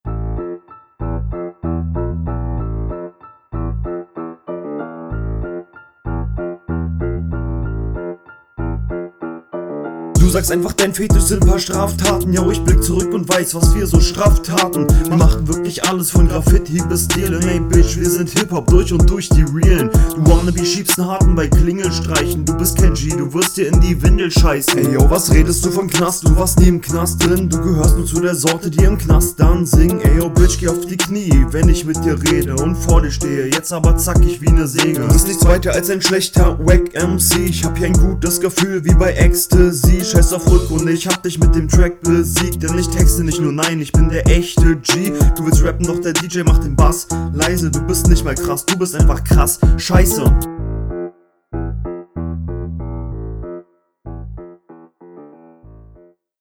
Der Flow ist nicht sonderlich ausgereift, alles wirkt ein bisschen abgehackt.